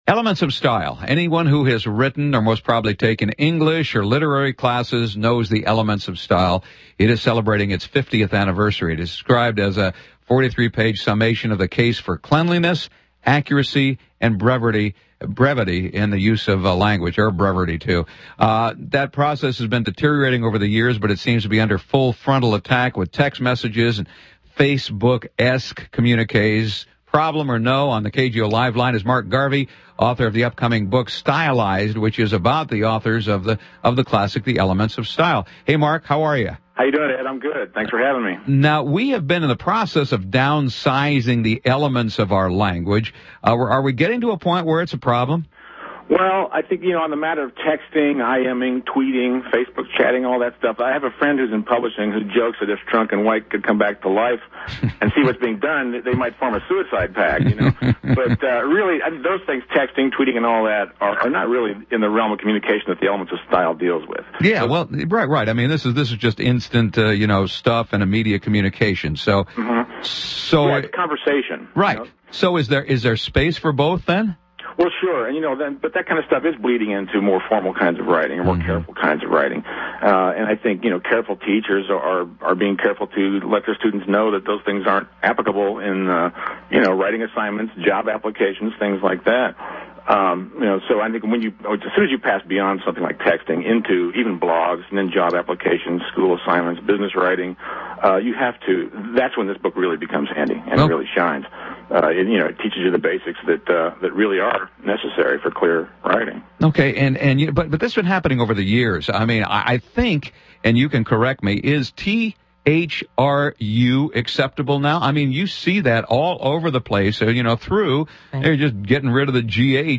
Interview on KGO, San Francisco (4 min) Interview from The Commentary (18 min) Interview on WXXI, Rochester (50 min) Interview on WVXU, Cincinnati (14 min)